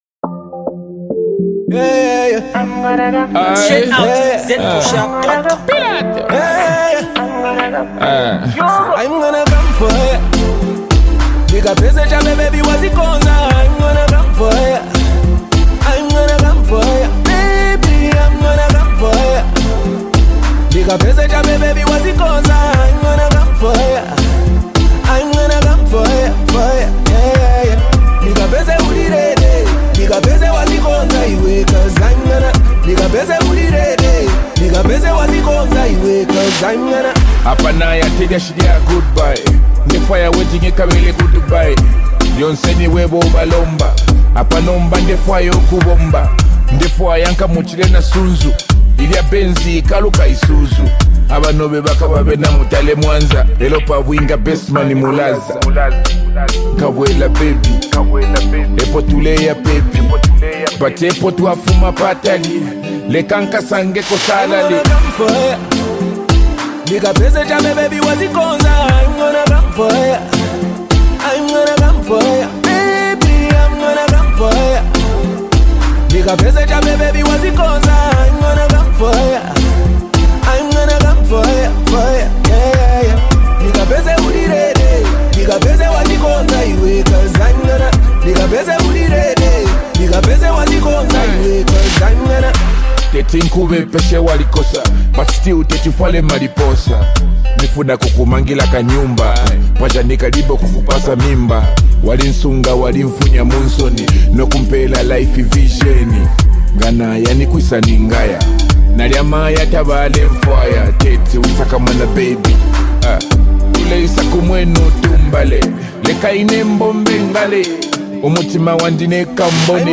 love masterpiece
joyful track